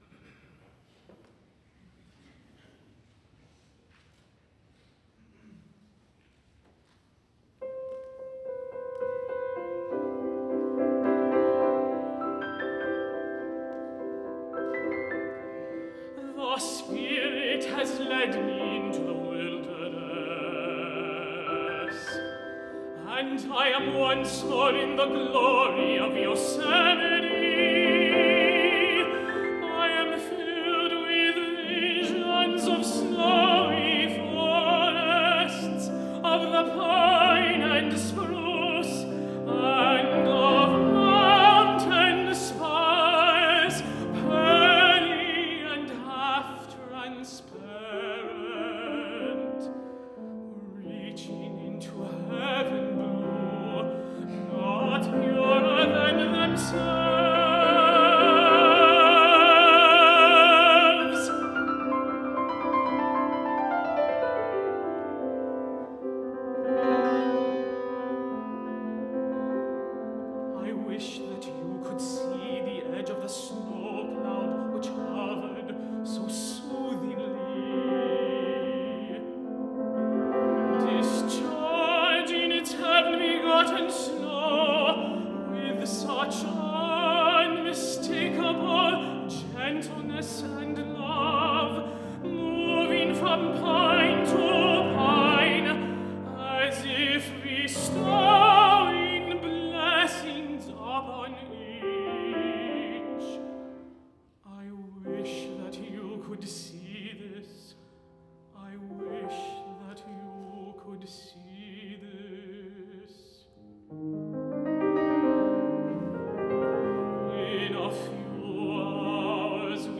for High Voice and Piano (2014)